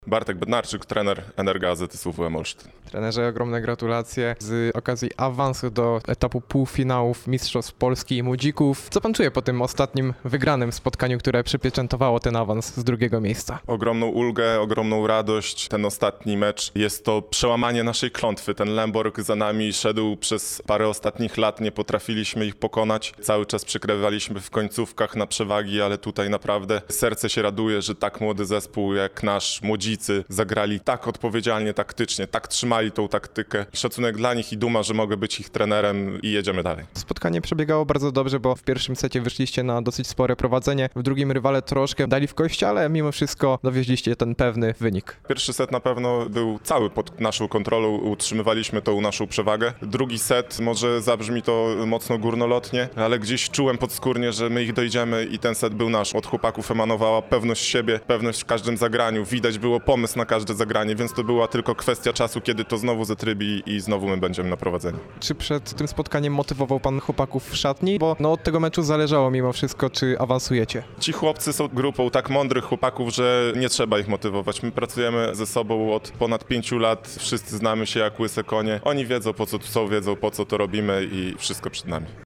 – mówił podekscytowany